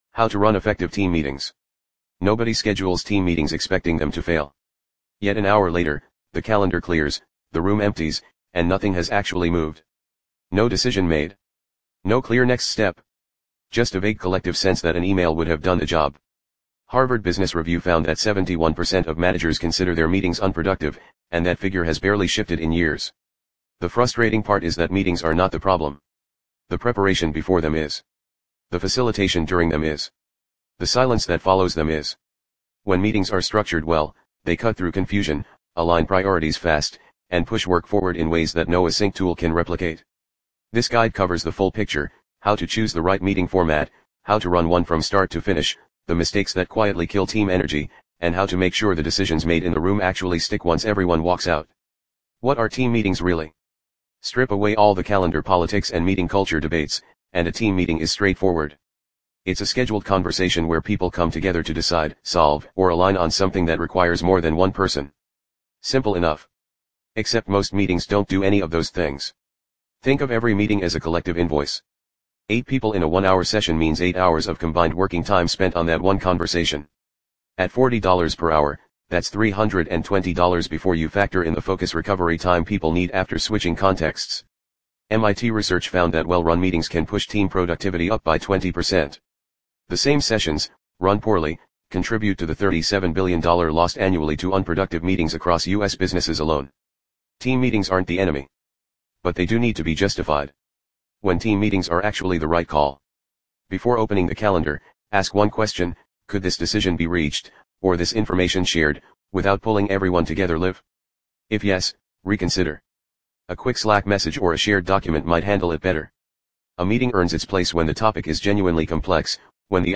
Read Aloud!